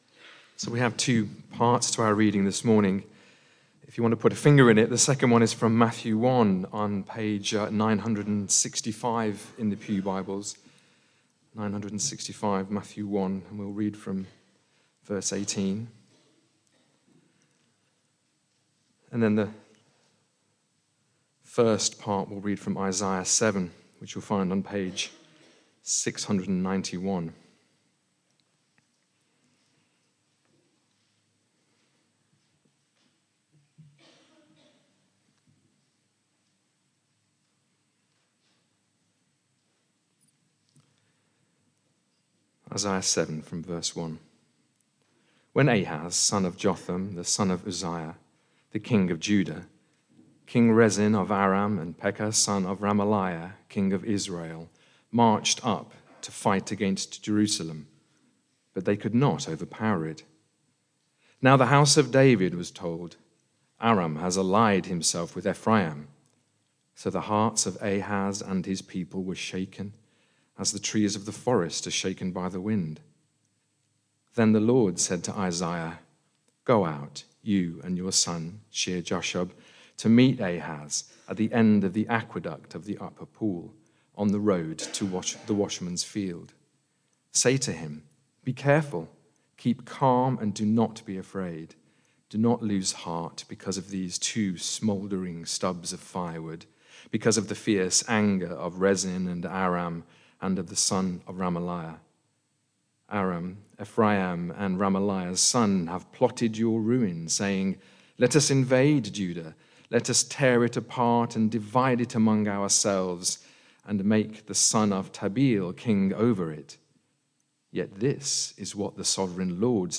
Barkham Morning Service
Advent Sermon